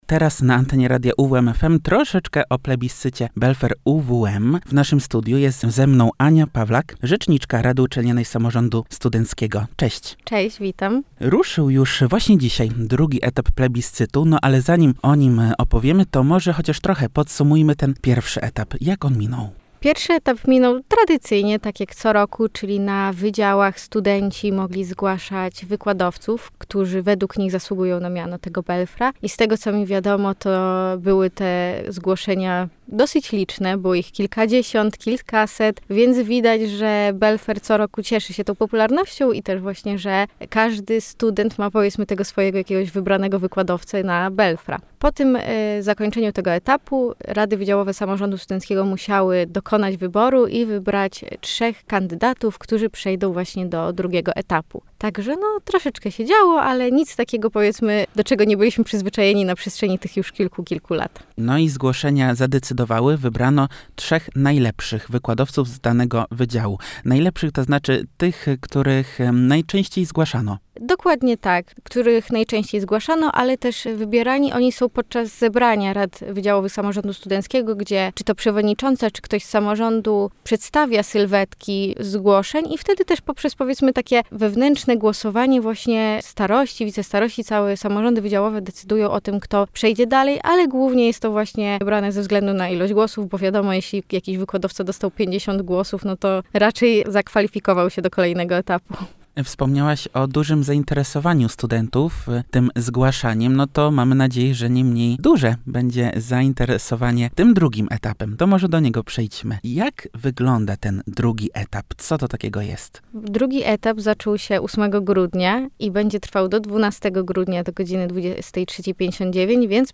– tłumaczyła w naszym studiu